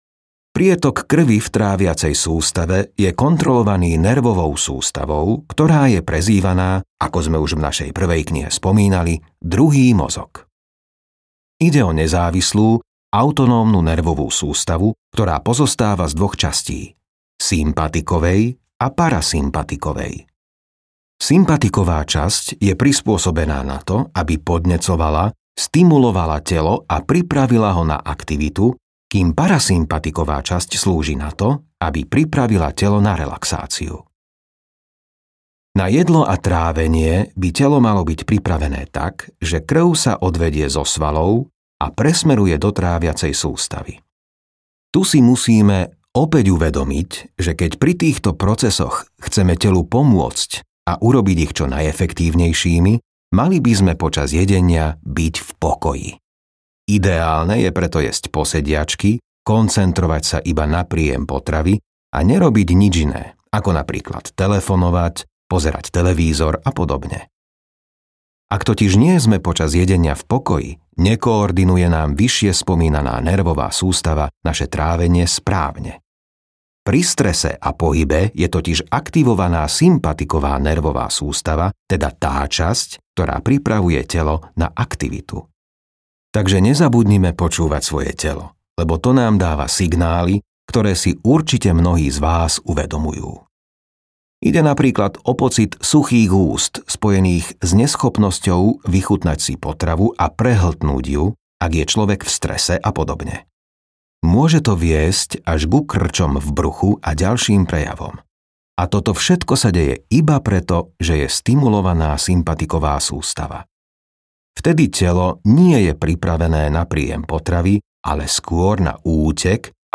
Audiokniha Zdravie bez liekov - Ladislav Kužela, Zuzana Čižmáriková | ProgresGuru